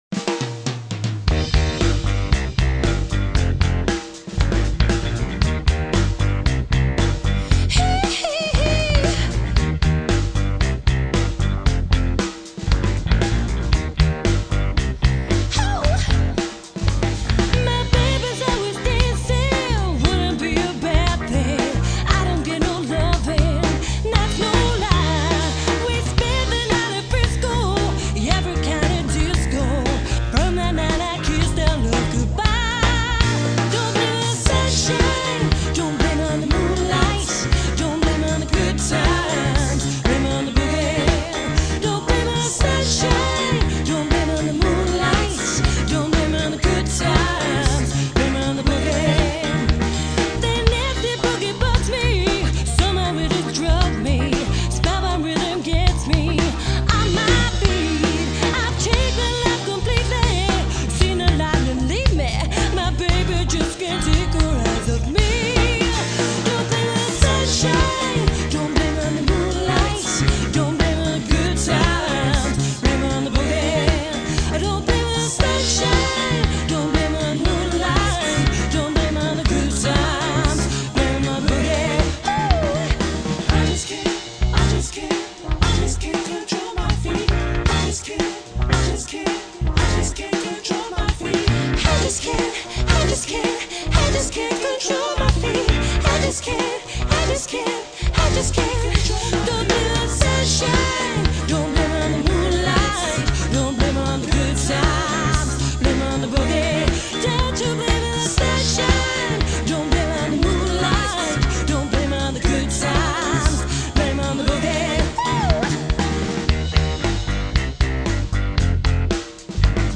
cover acts
Male singer/ keyboard player
attractively smooth voice